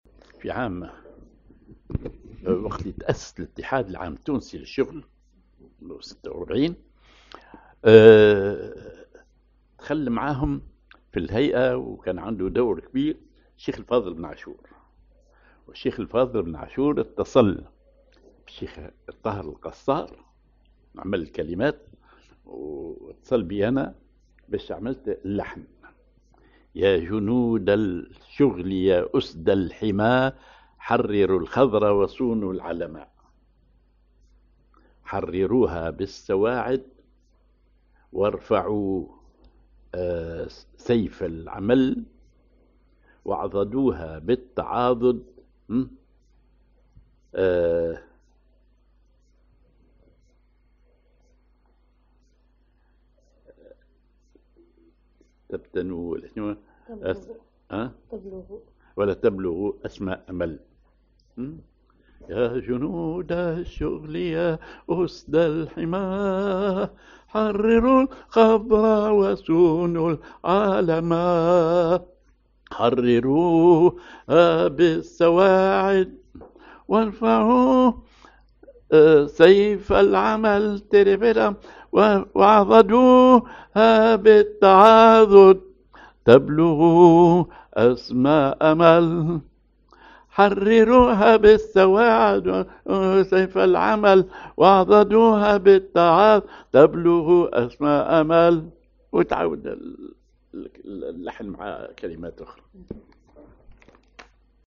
Maqam ar ماجور على النوا
genre نشيد